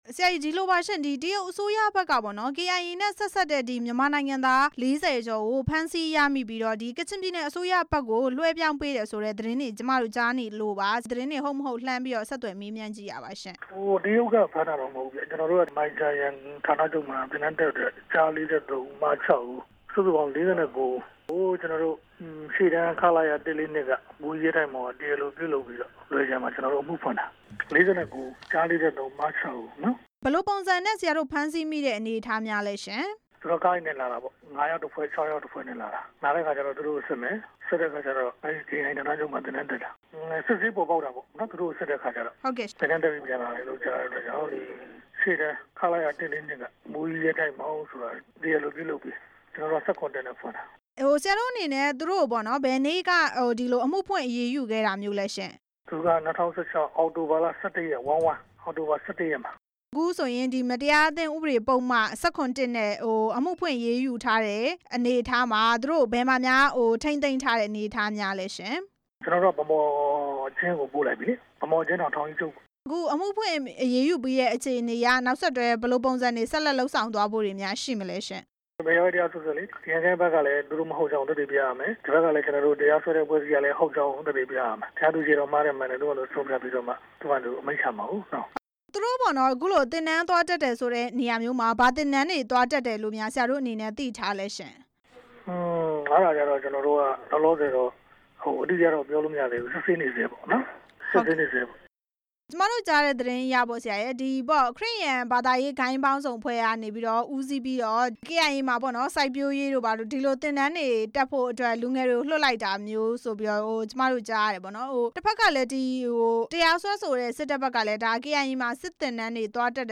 ဆက်သွယ် မေးမြန်းထားပါတယ်။